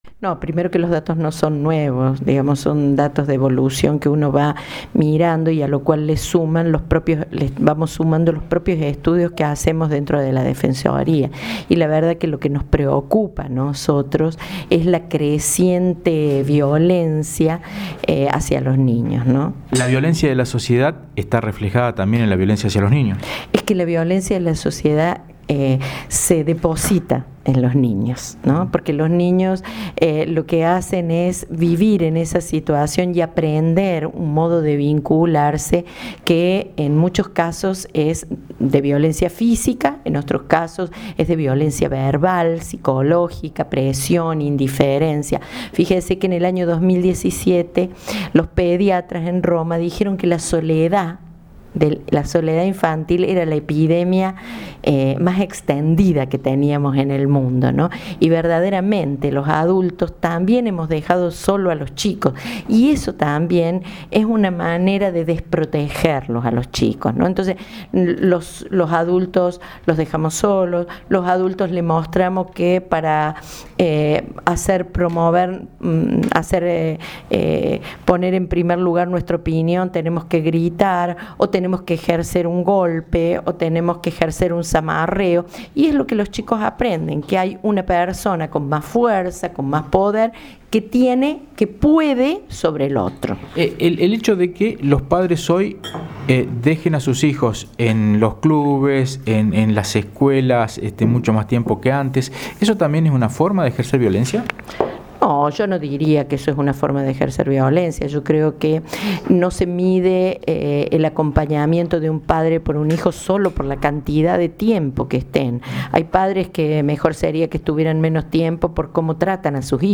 Audio: Amelia López (Defensora de Niñas, Niños y Adolescentes).